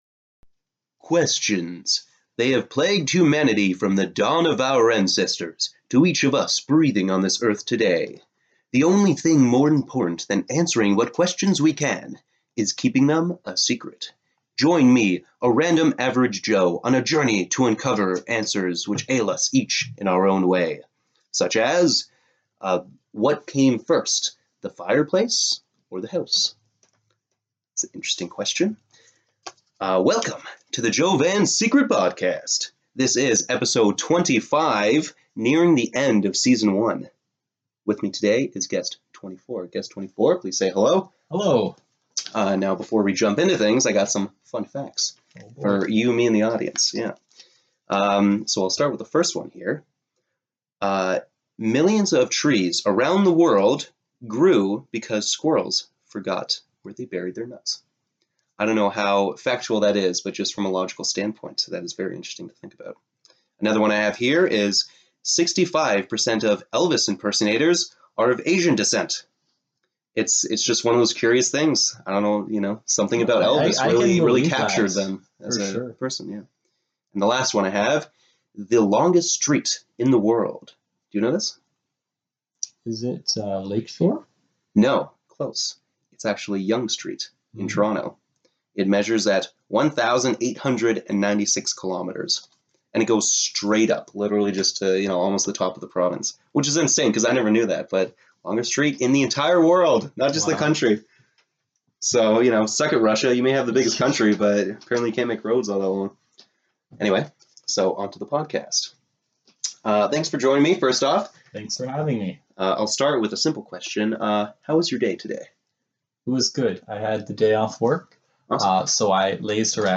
This episode I speak to a young, passionate man in regards to plant care.